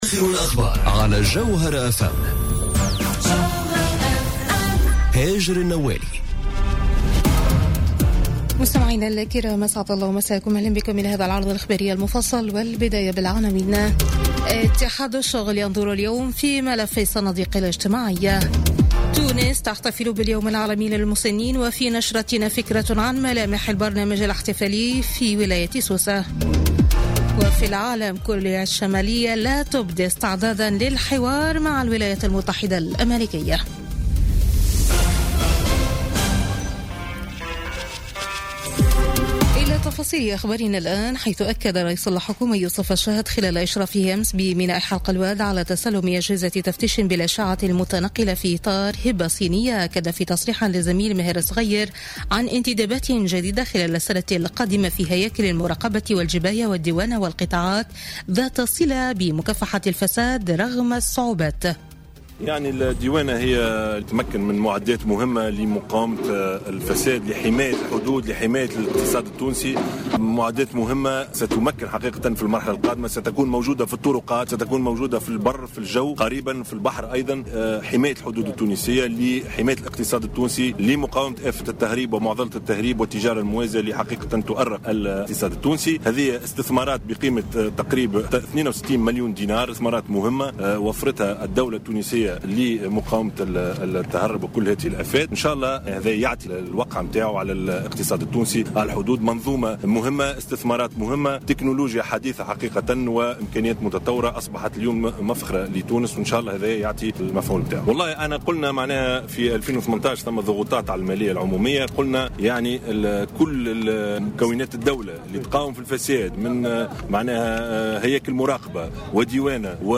نشرة أخبار منتصف الليل ليوم الأحد 1 أكتوبر 2017